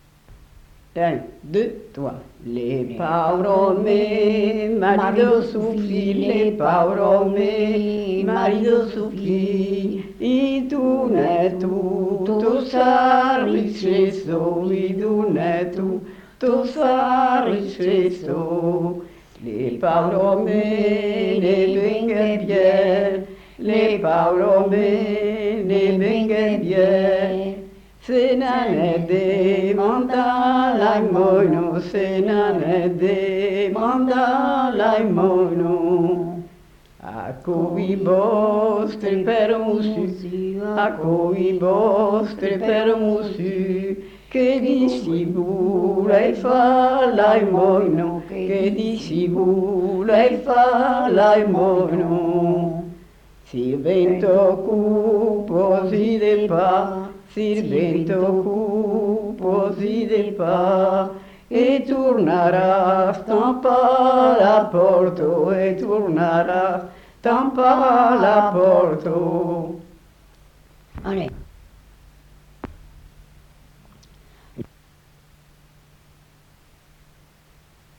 Aire culturelle : Cabardès
Lieu : Mas-Cabardès
Genre : chant
Effectif : 2
Type de voix : voix de femme
Production du son : chanté